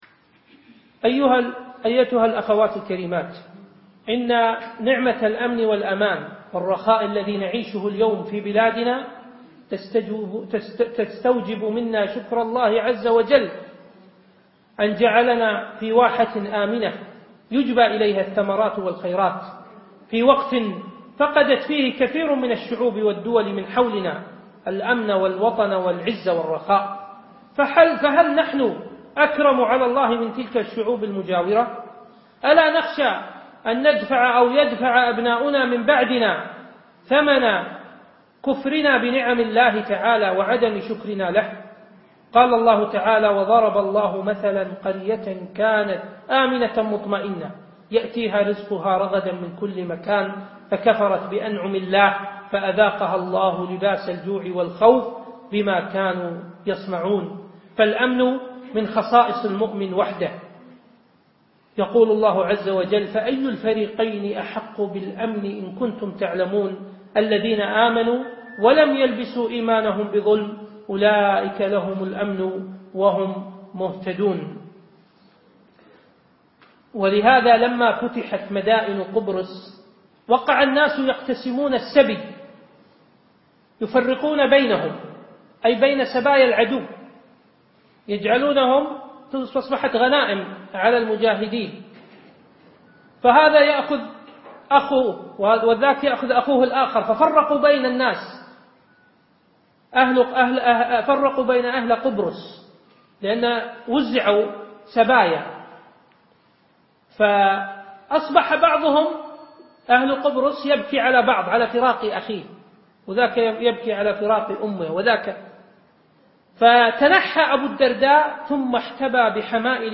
مقطتف من محاضرة حب الأوطان